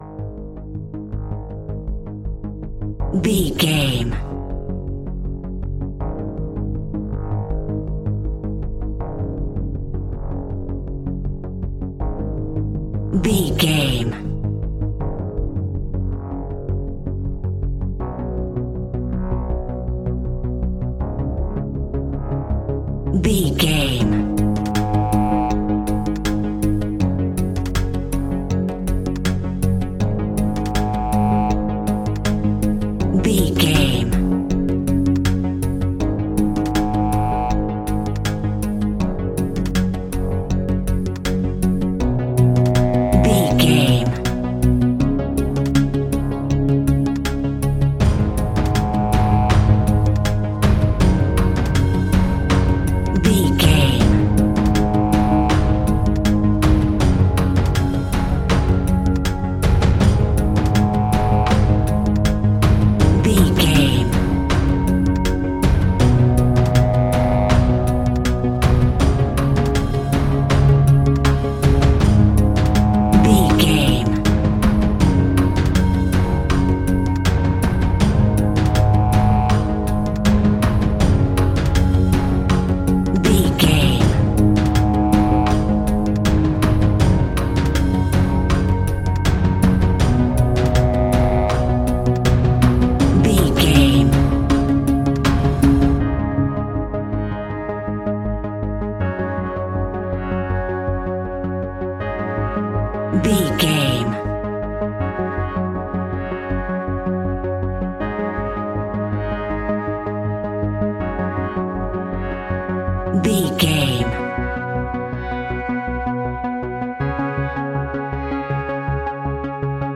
Aeolian/Minor
A♭
ominous
dark
haunting
eerie
synthesizer
drum machine
horror music
Horror Pads